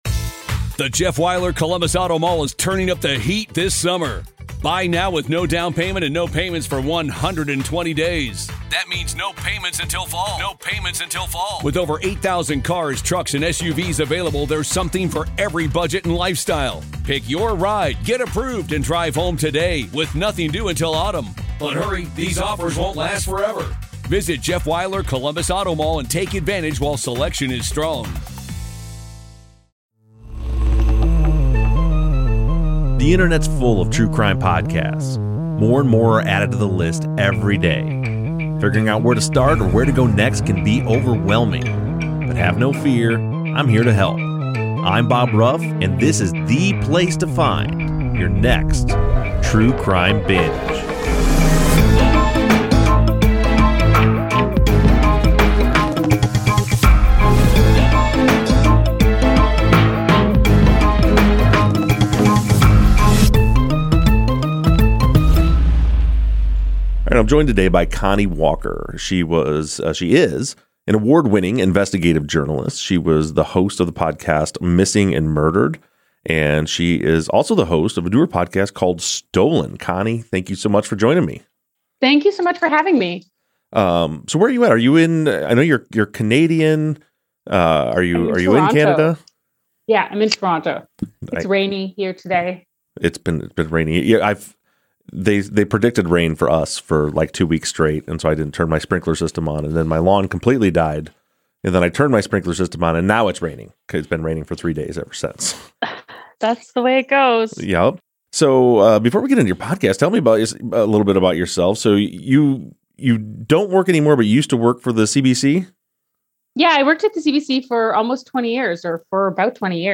interviews award-winning investigative journalist